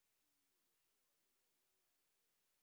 sp02_train_snr30.wav